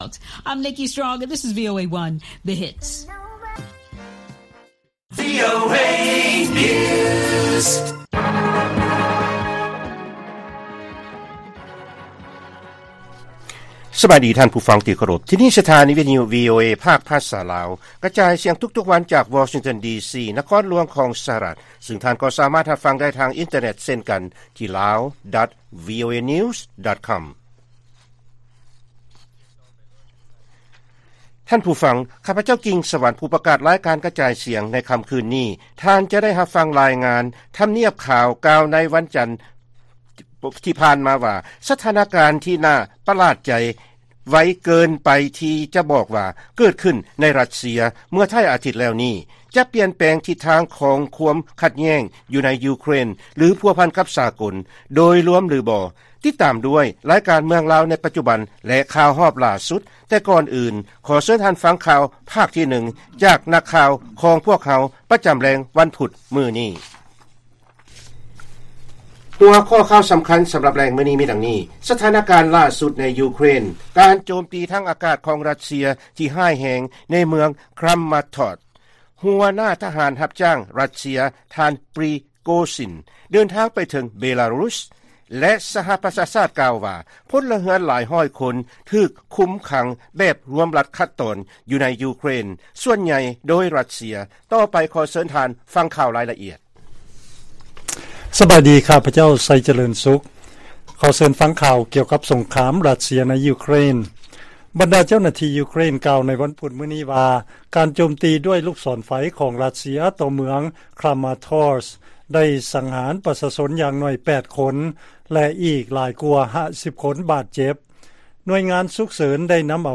ລາຍການກະຈາຍສຽງຂອງວີໂອເອ ລາວ: ສະຖານະການຫຼ້າສຸດໃນຢູເຄຣນ ການໂຈມຕີທາງອາກາດຂອງຣັດເຊຍ ທີ່ຮ້າຍແຮງ ໃນເມືອງຄຣາມາທອຣສ໌